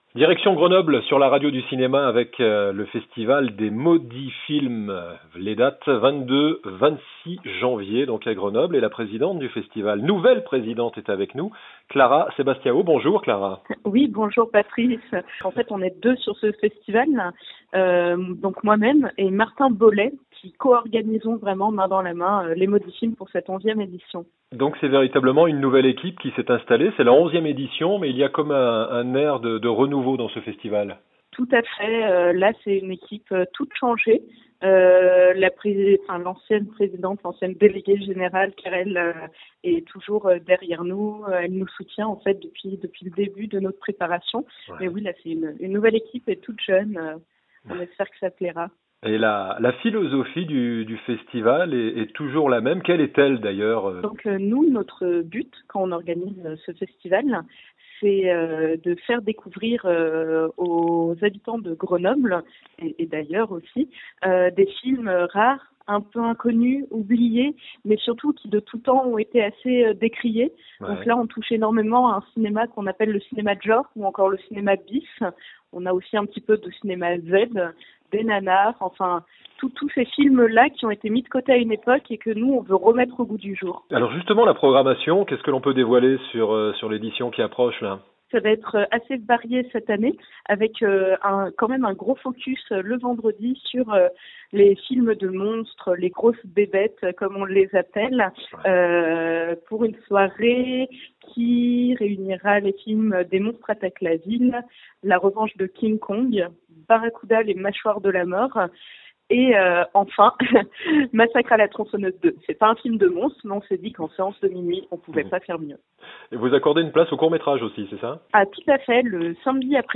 Podcasts cinéma : interviews | La Radio du Cinéma